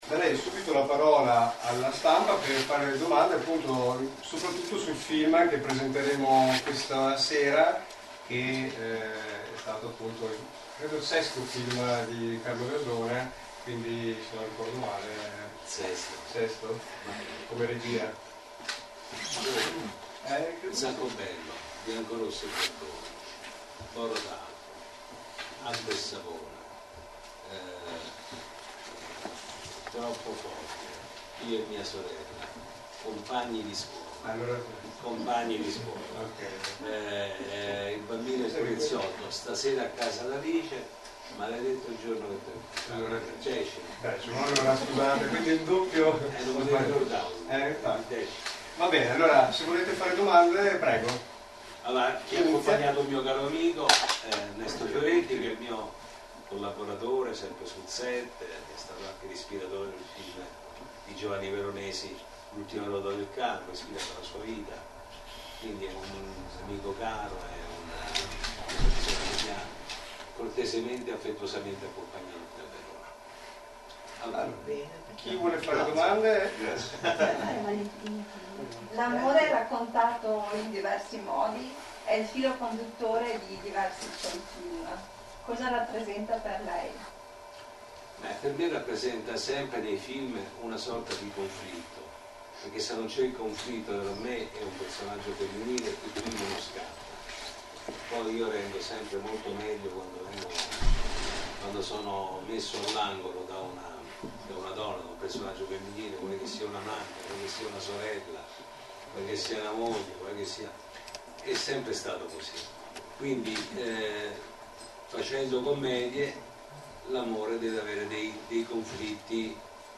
Conferenza stampa con Carlo Verdone - PopCorn - FuoriAulaNetwork
In occasione del Love Film Fest i ragazzi di PopCorn, sono riusciti a partecipare a molte proiezioni, riuscendo a raccogliere la registrazione della conferenza stampa nella Libreria Antiquaria di Verona, che ha avuto l’onore di ospitare l’attore e regista Carlo Verdone!